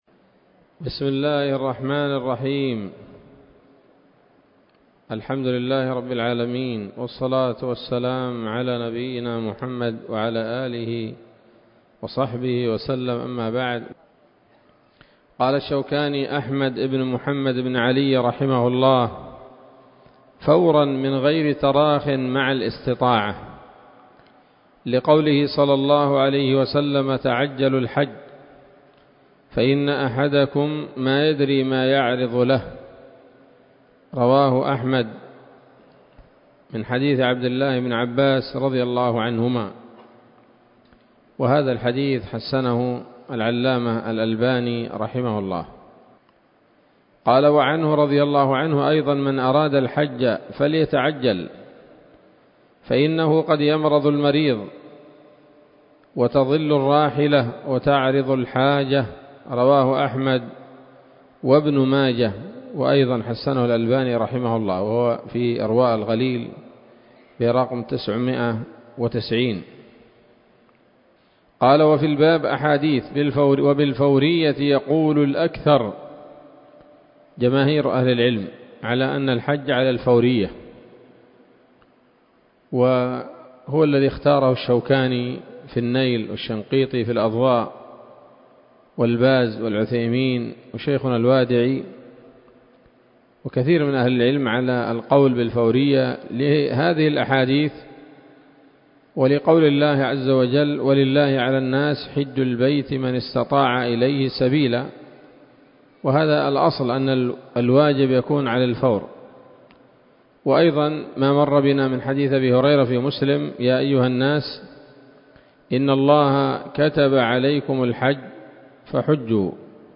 الدرس الثاني من كتاب الحج من السموط الذهبية الحاوية للدرر البهية